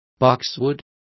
Complete with pronunciation of the translation of boxwood.